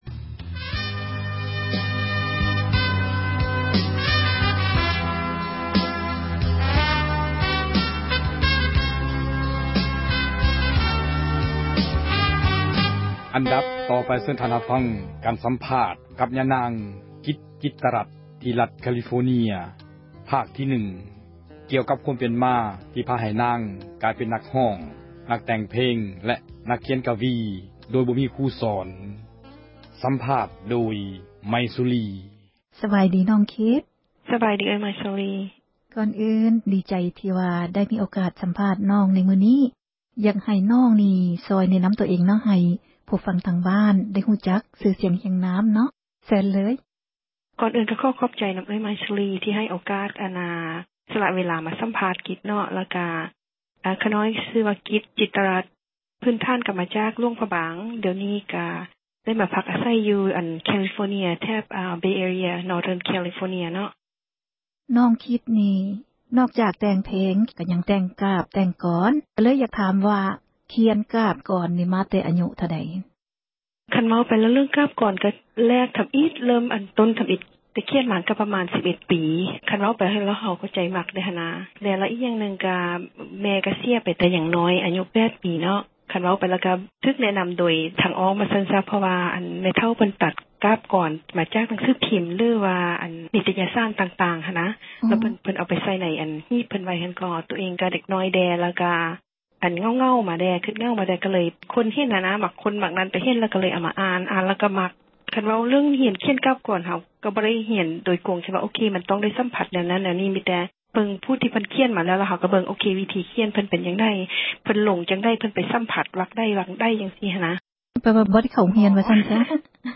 ສັມພາດສິລປິນຍິງ